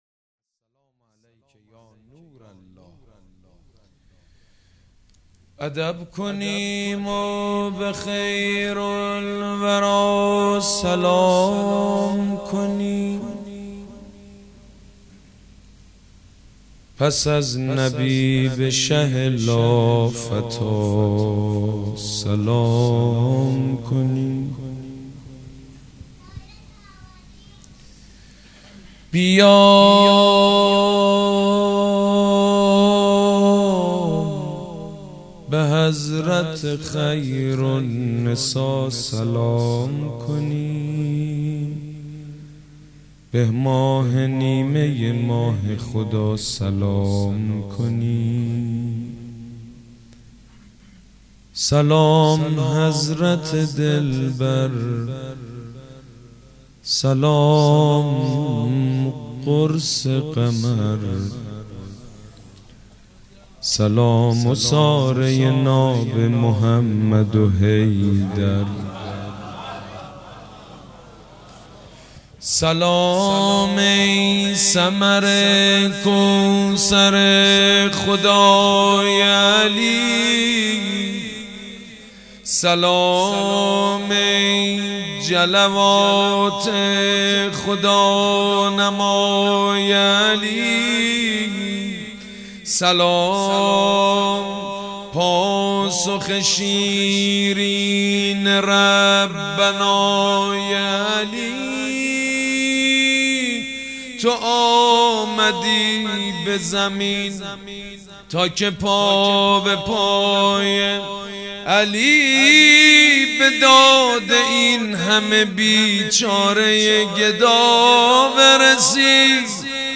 مدح: ادب کنیم و به خیرالورا سلام کنیم